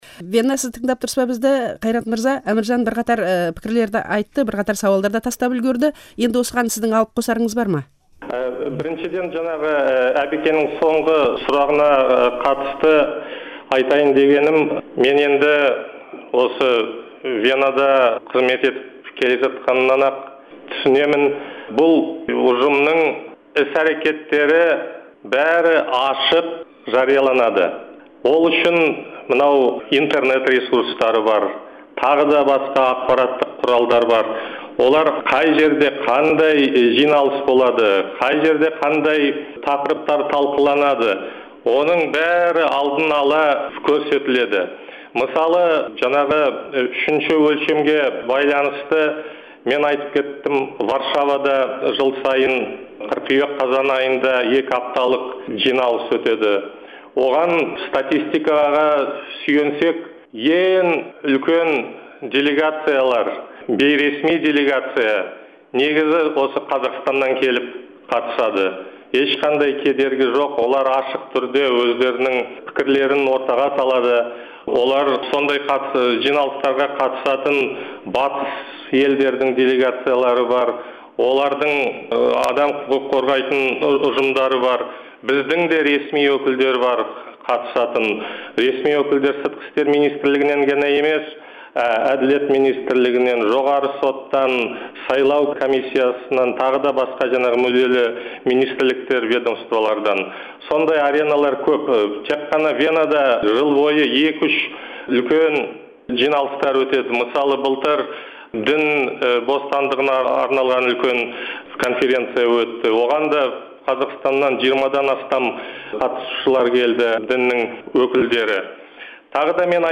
Дөңгелек үстел жазбасын осы жерден тыңдаңыз.